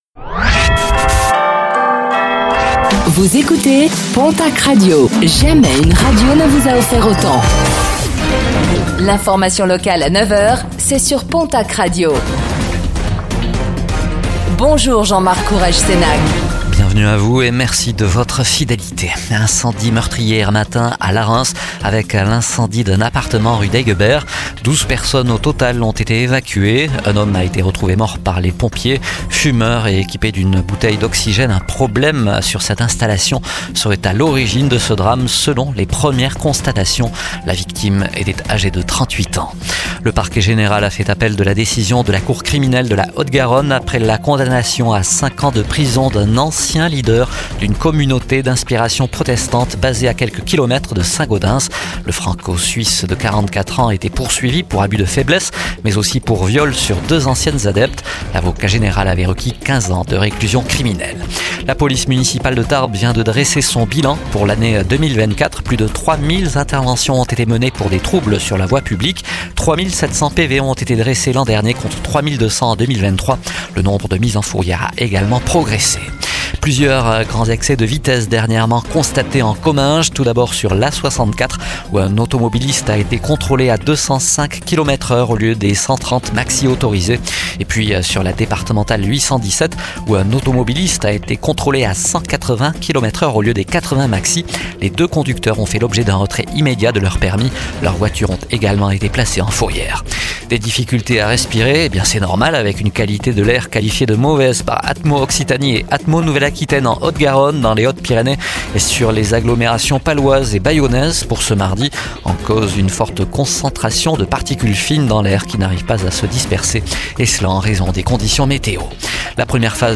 Infos | Mardi 04 février 2025